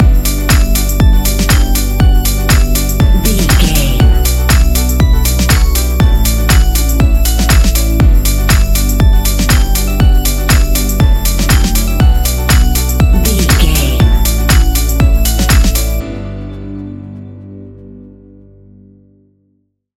Ionian/Major
house
electro dance
synths
techno
trance
instrumentals